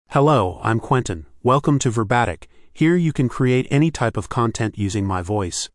MaleEnglish (United States)
Quentin is a male AI voice for English (United States).
Voice sample
Listen to Quentin's male English voice.
Quentin delivers clear pronunciation with authentic United States English intonation, making your content sound professionally produced.